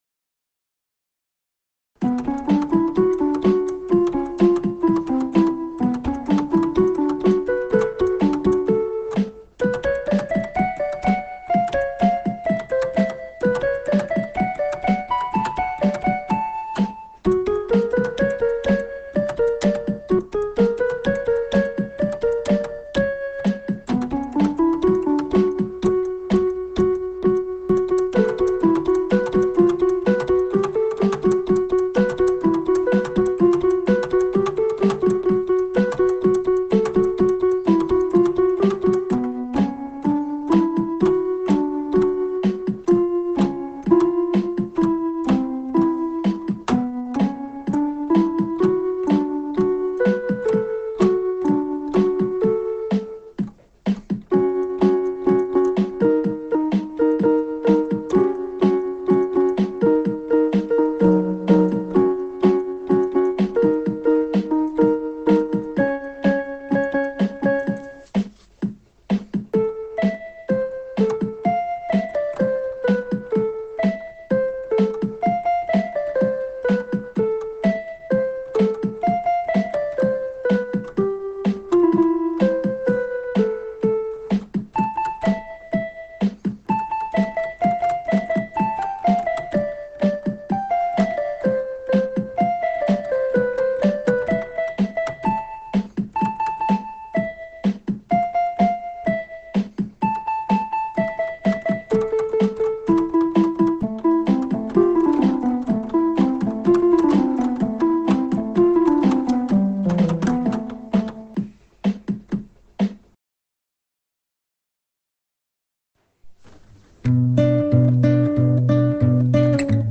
October 2022: Synth Phase Bought a vintage 70s German synth.
Snippets from a 30-minute jam
Guitar Loops
French horn jam
Chaotic neutral - 7:15 - Learned to Fly: First vocal attempt.
It worked ;> - 12:43 - Camp Story: Audacity crashed mid-recording.
You can still hear voice, pan and timbre change all the time because of that So here we are.